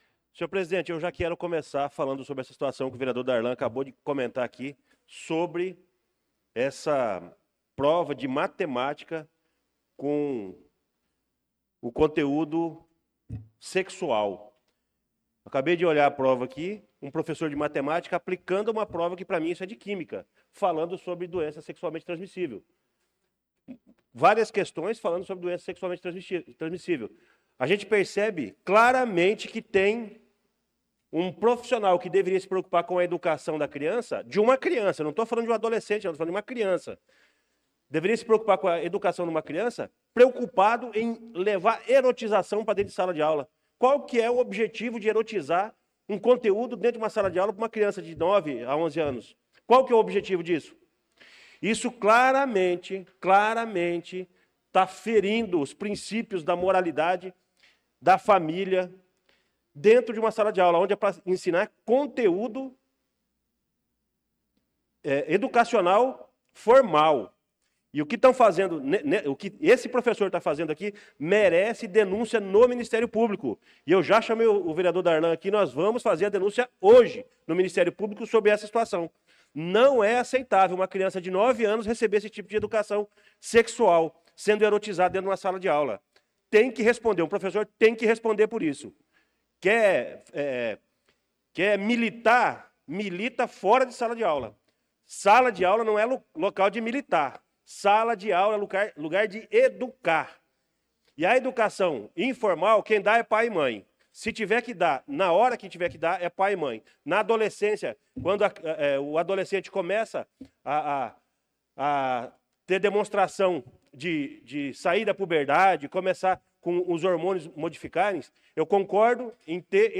Pronunciamento do vereador Luciano Silva na Sessão Ordinária do dia 04/08/2025.